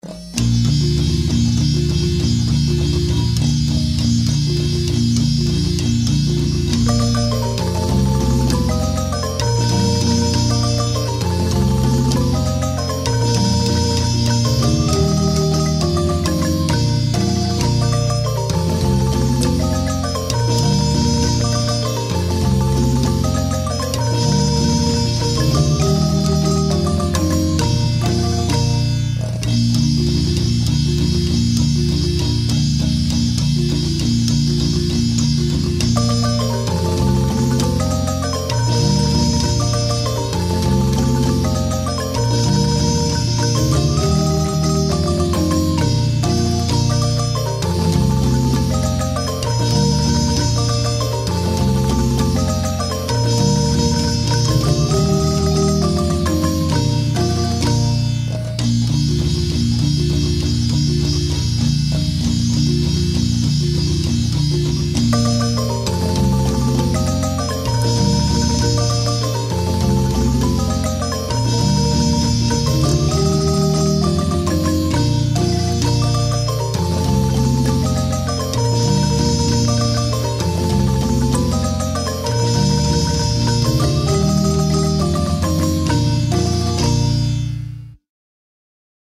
Las obras, ordenadas de 1 a 8, fueron grabadas en estudio durante el año 2006 con la marimba escuadra (marimba grande y un tenor) de la Casa de la Cultura de Santa Cruz, Guanacaste.
MUSICA, GUANACASTE, MARIMBA